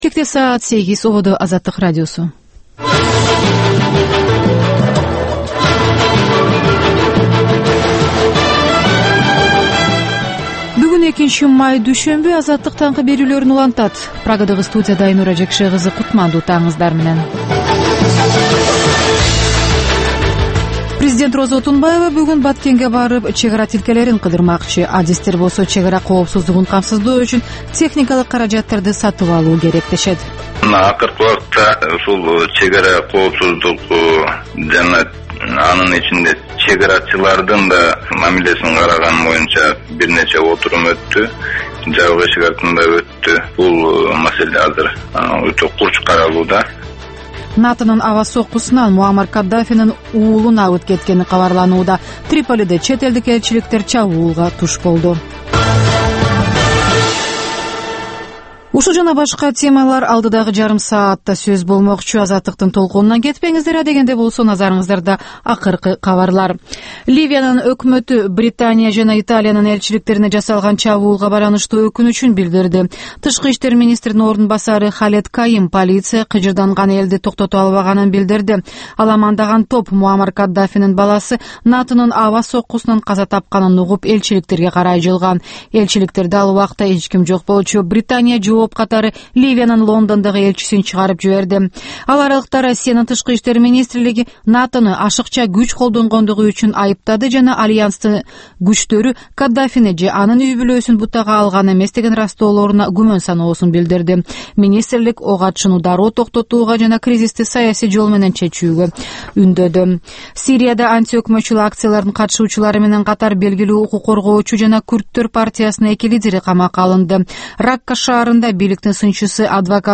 Таңкы 8деги кабарлар